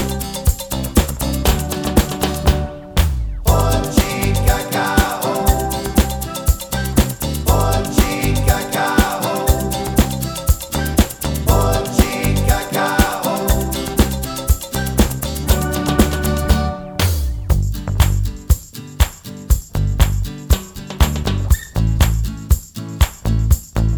no Backing Vocals Glam Rock 3:16 Buy £1.50